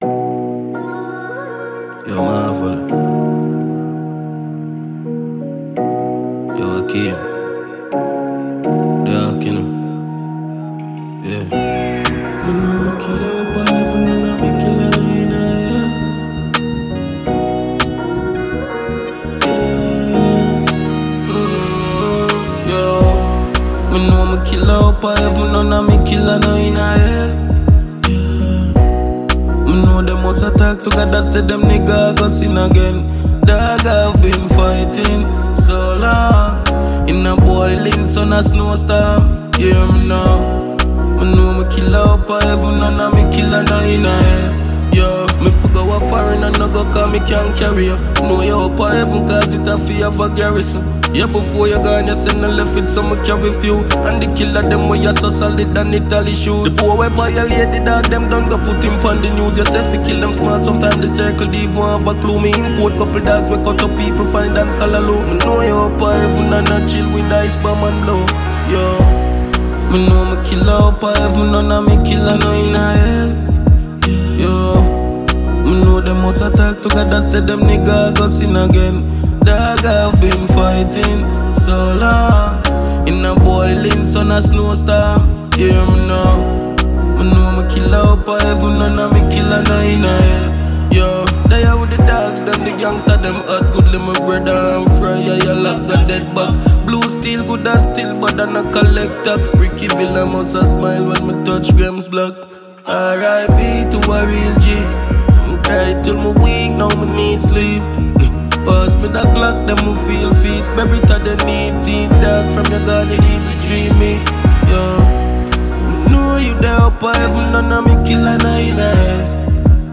Jamaican award winning dancehall act